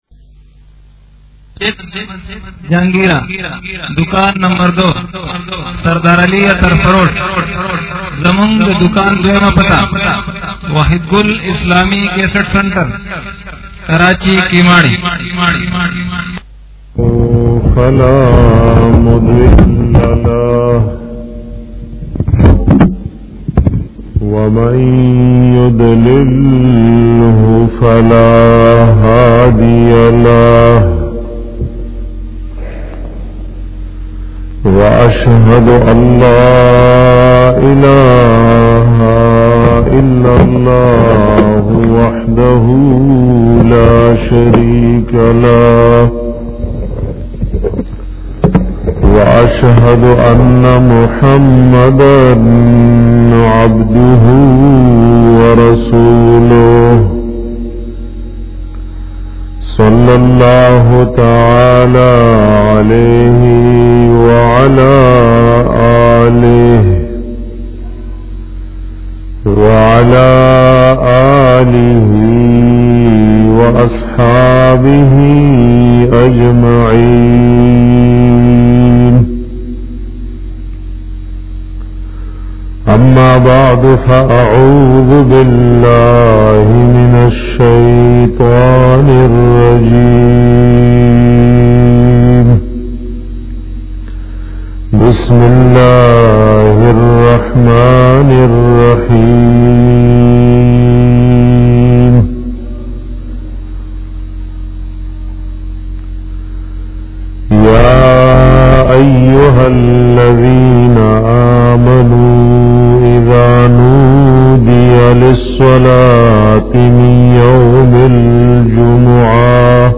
bayan pa bara da fazilat da darood sarif 2